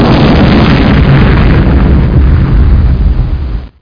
explode4.mp3